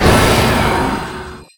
Magic_Spell05.wav